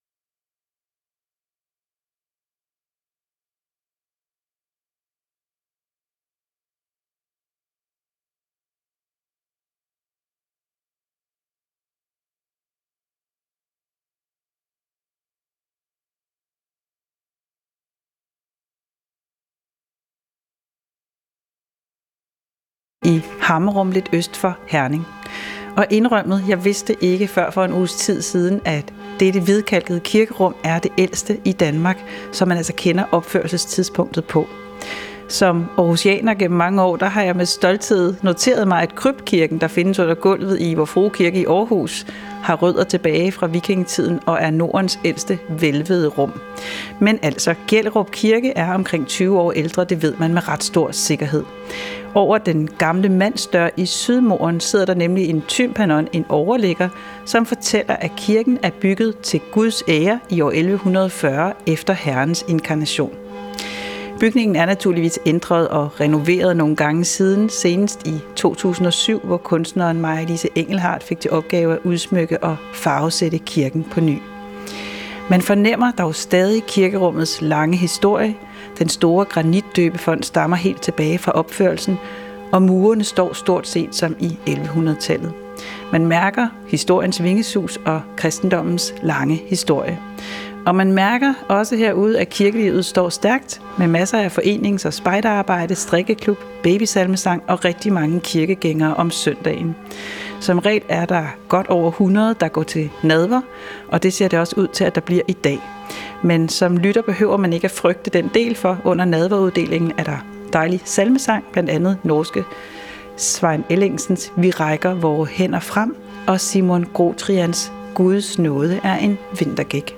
Direkte transmission af dagens højmesse fra en af landets kirker.